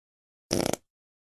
Fart Sound Effect Free Download
Fart